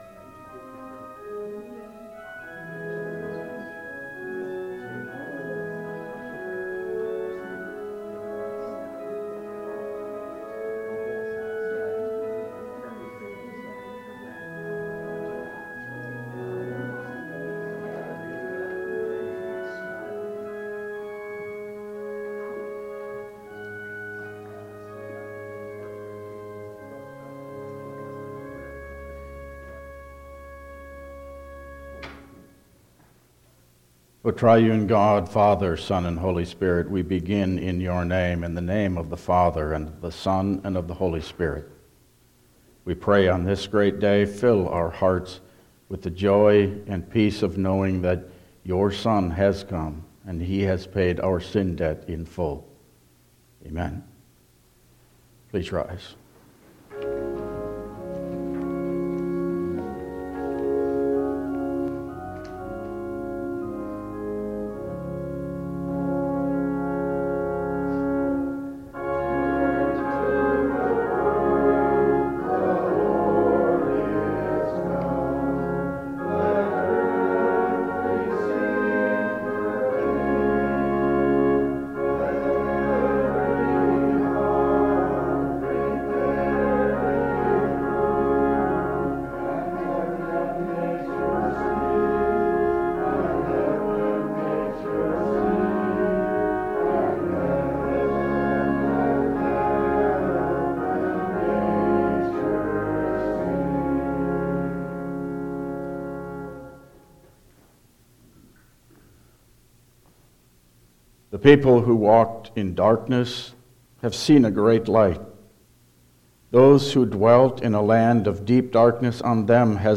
Download Files Printed Sermon and Bulletin
Service Type: Christmas Day